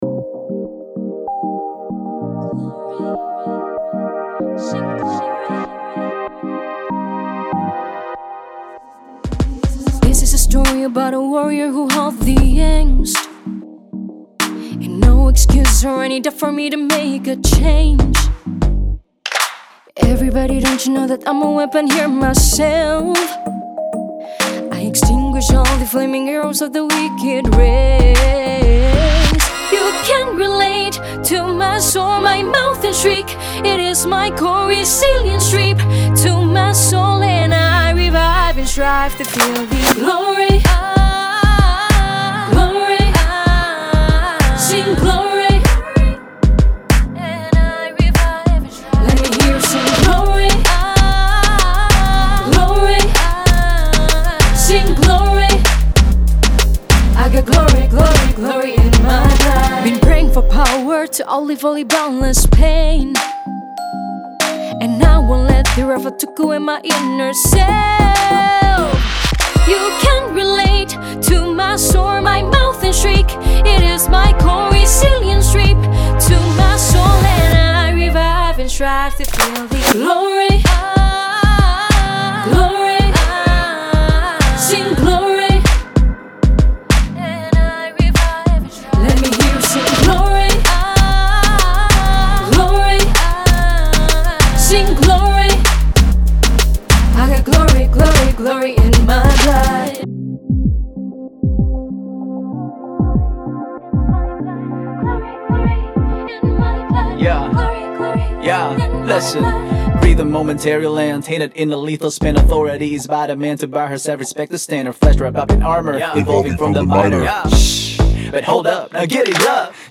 semakin terkesan glamour tapi tetap nyaman untuk didengarkan
RnB/Rap